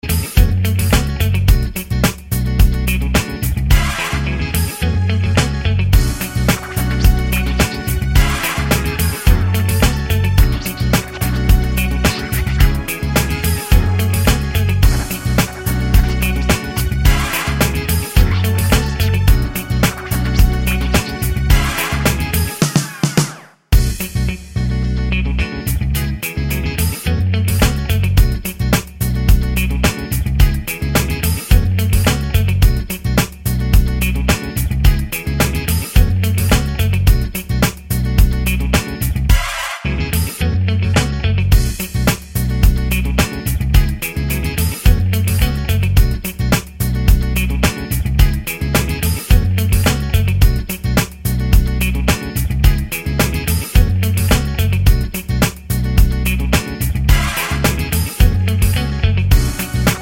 no Backing Vocals R'n'B / Hip Hop 3:38 Buy £1.50